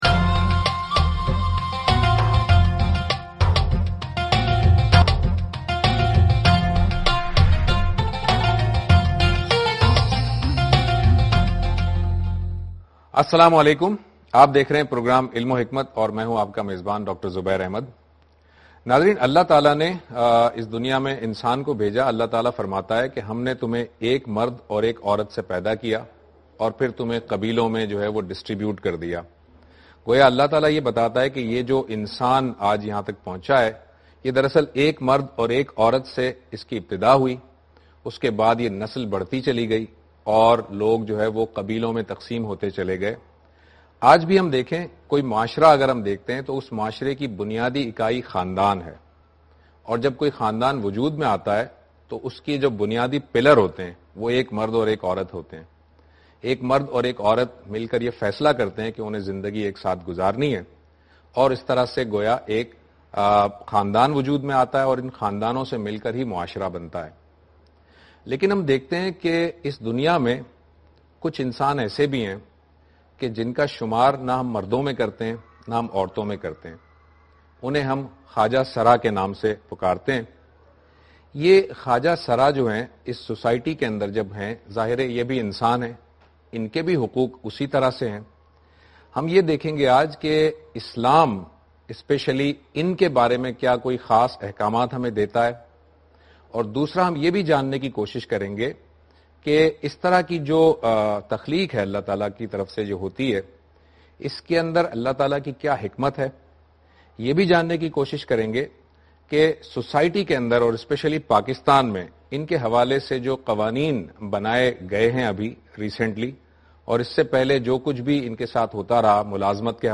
In this program Javed Ahmad Ghamidi speaks about "Transgenders and Society" in program Ilm o Hikmat on Dunya News.